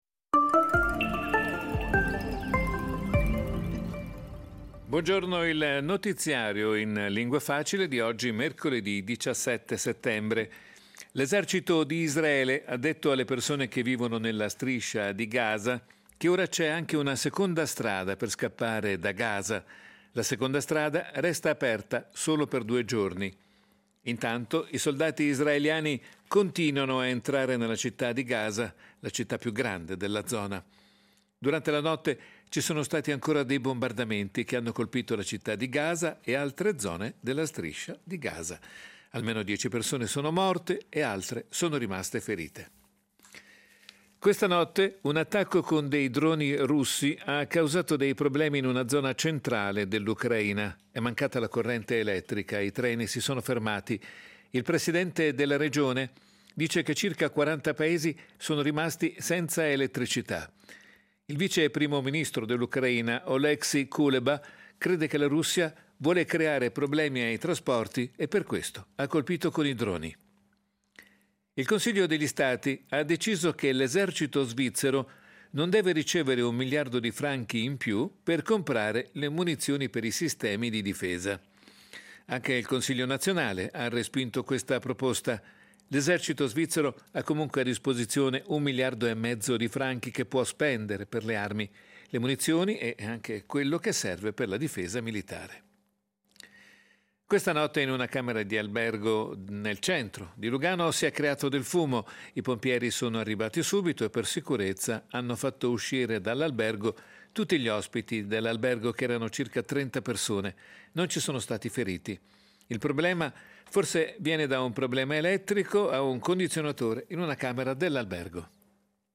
Notizie in lingua facile